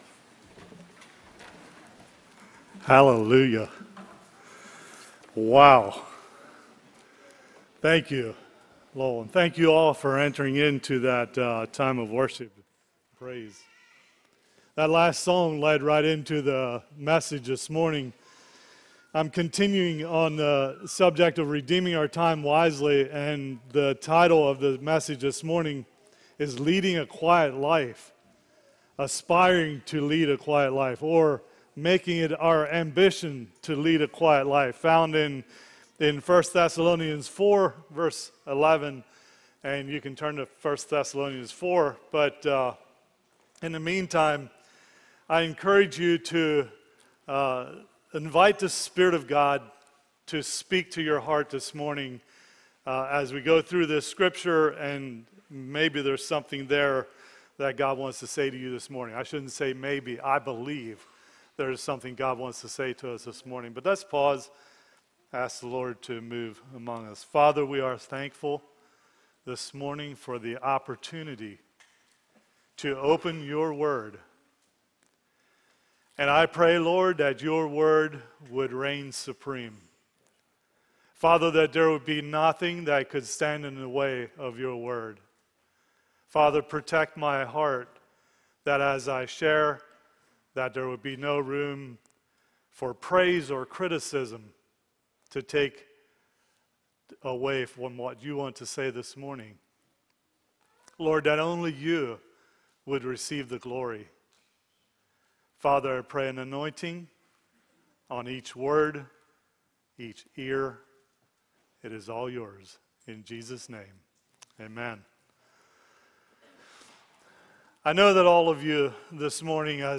A message from the series "Sunday Morning - 10:30."
From Series: "Sunday Morning - 10:30"